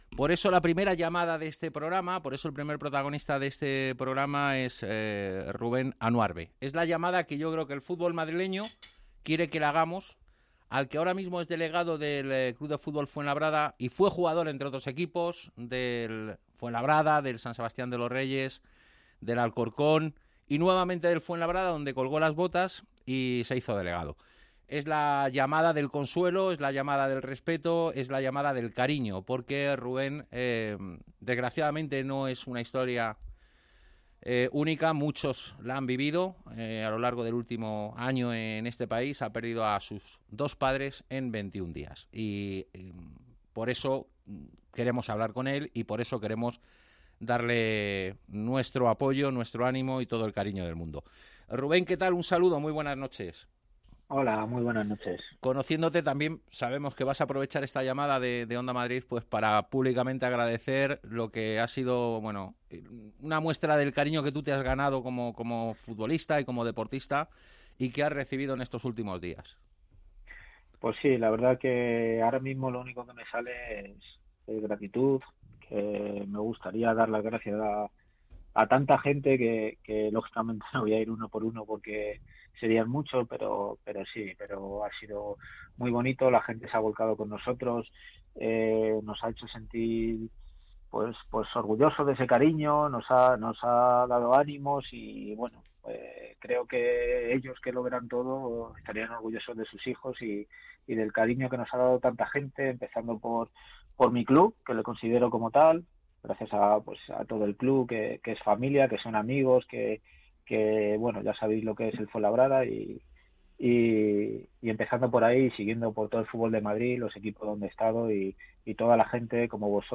Una entrevista en la que desgraciadamente muchos se sentirán reflejados.